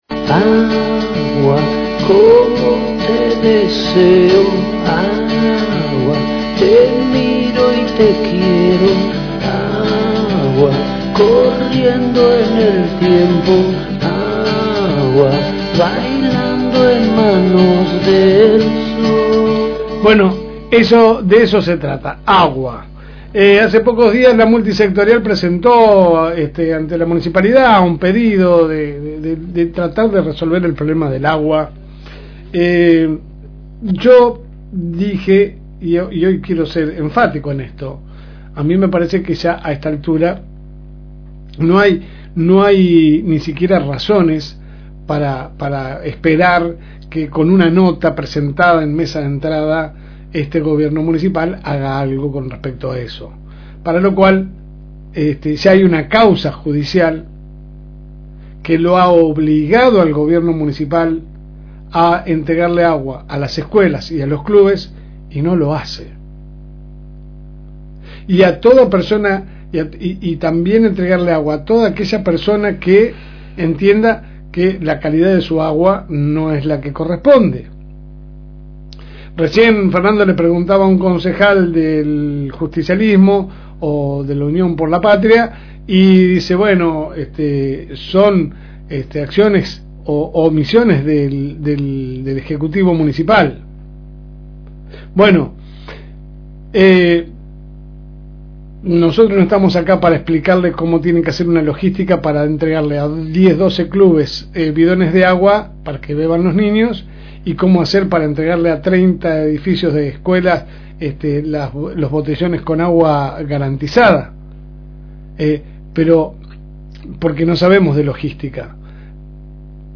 Editorial LSM